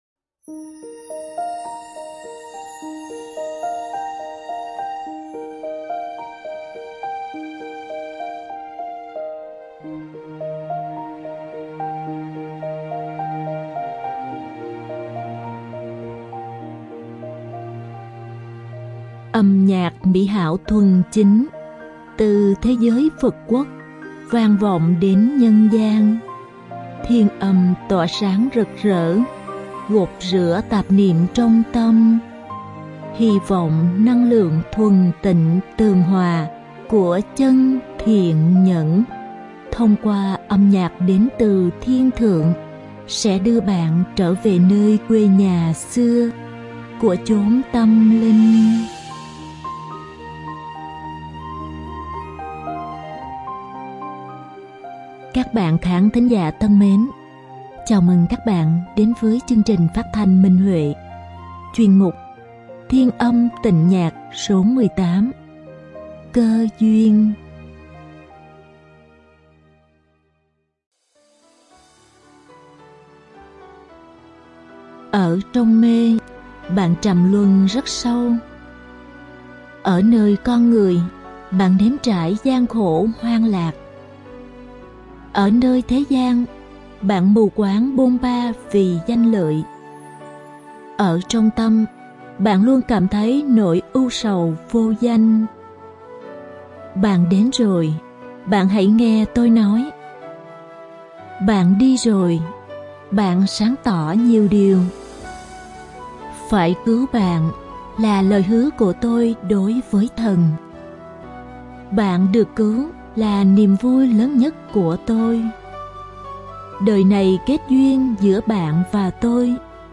Đơn ca nữ
Màn hợp tấu